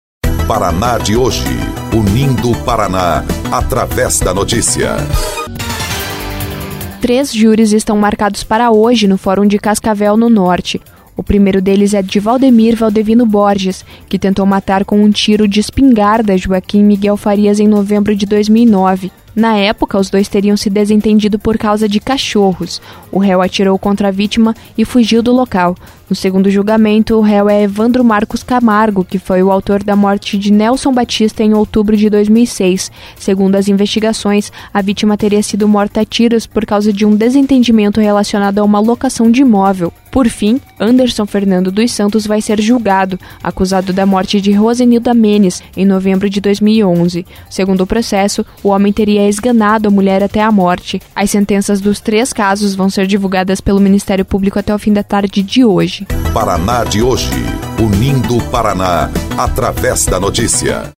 13.06 – BOLETIM – Três acusados de homicídio e tentativa de homicídio vão a júri hoje em Cascavel